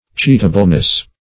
Cheatableness \Cheat"a*ble*ness\, n. Capability of being cheated.